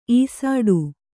♪ īsāḍu